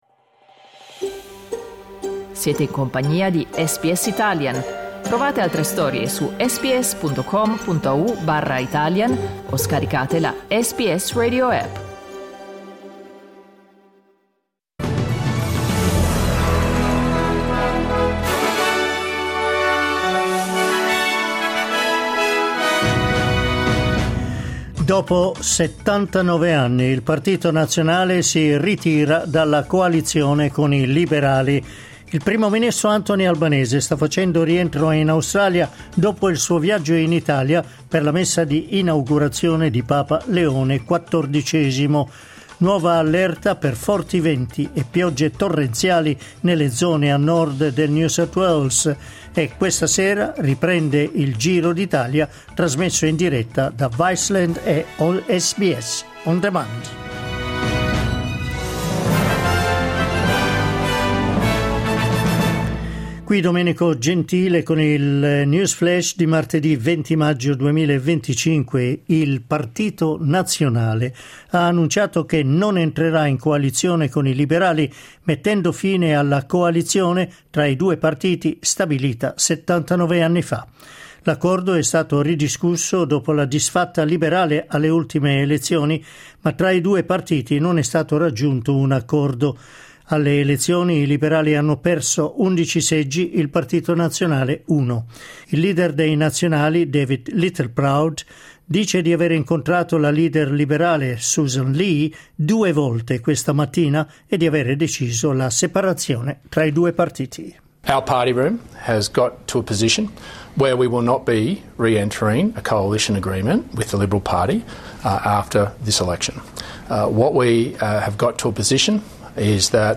News flash martedì 20 maggio 2025
L’aggiornamento delle notizie di SBS Italian.